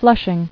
[Flush·ing]